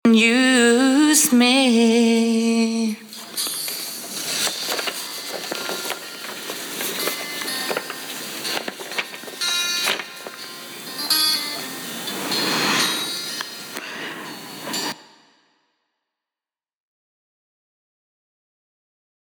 Guten Abend, wir haben in unserer Gemeinde ein Stück aufgenommen und würden uns sehr freuen, wenn ihr das Mix & Mastering dafür übernehmen könntet...
Dieses hier ist vollkommen übersteuert und clippt.
Man hört zwar, dass das keine geschulte Stimme ist, aber sie passt zu den Stücken, die Ihr gewählt habt.